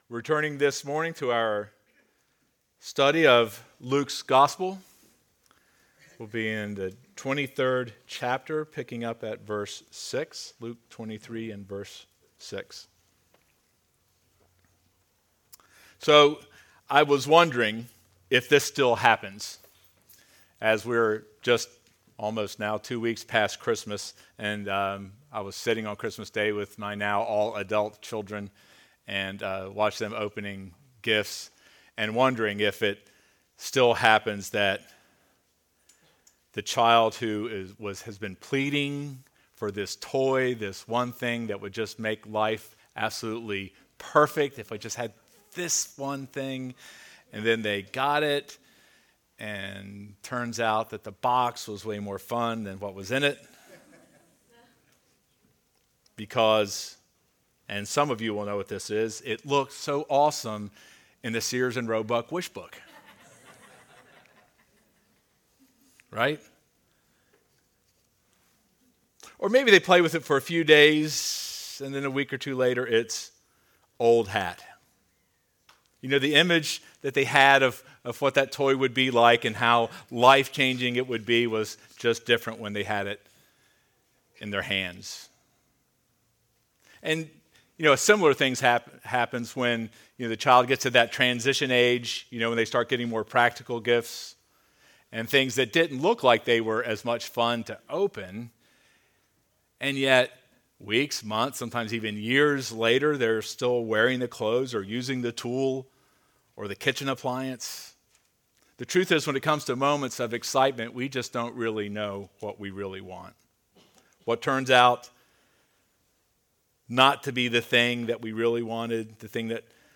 Sermon Notes God is Holy Nothing else is Holy